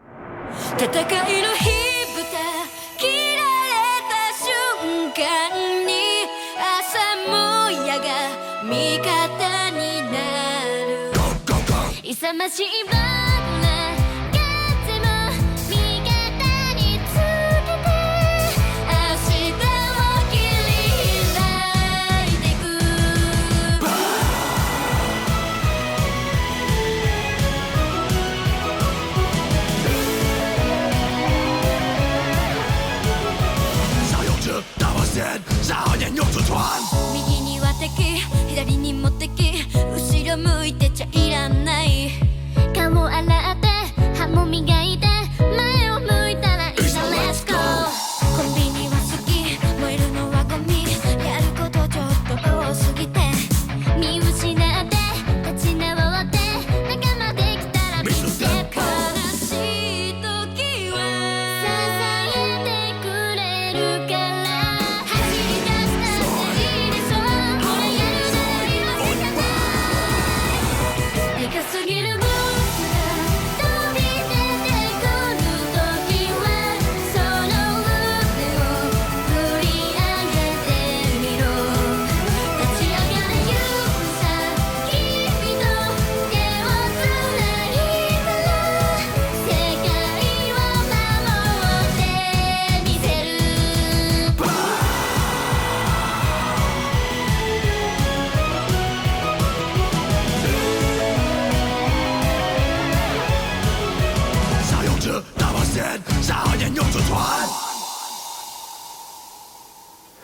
BPM170
Audio QualityPerfect (Low Quality)
Chinese song title from a Japanese group